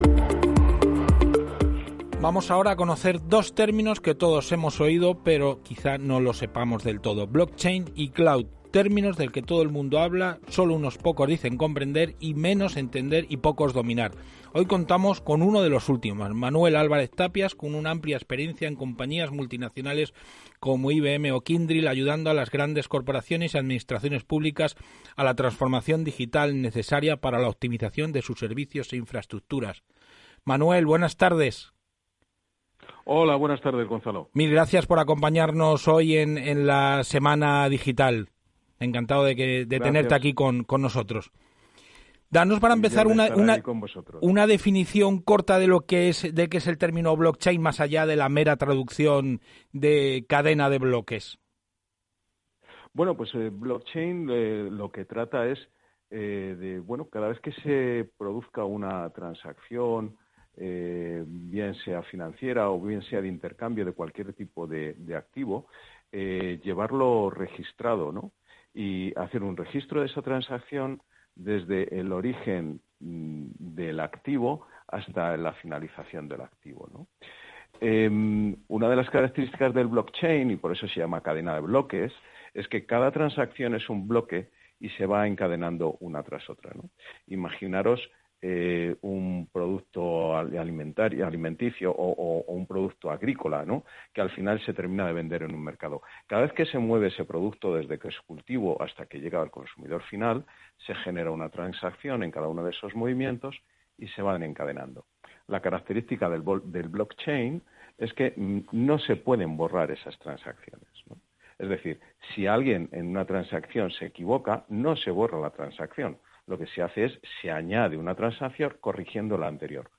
Descargar la entrevista: Descargar BLOCKCHAIN y CLOUD, términos del que todo el mundo habla y solo unos pocos dicen comprender, menos entender y pocos dominar.